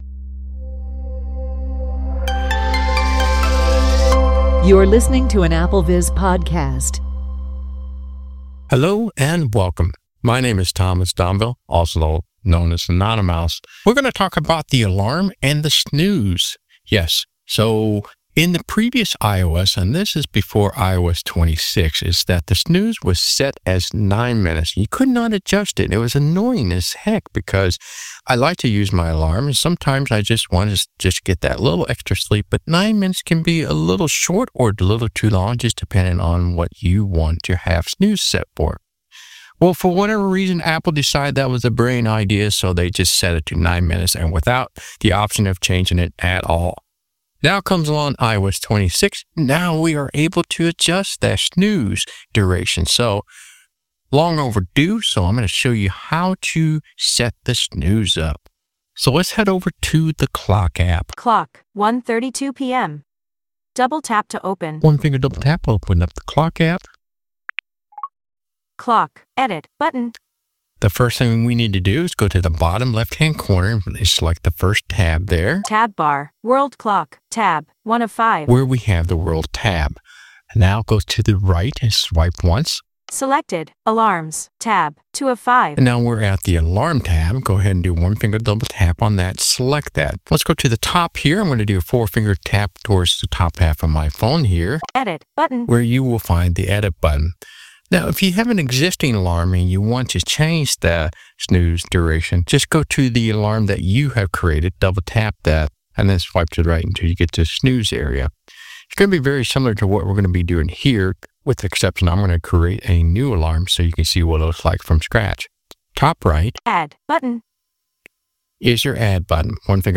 VoiceOver-Specific Notes (from the walkthrough)
Walk-through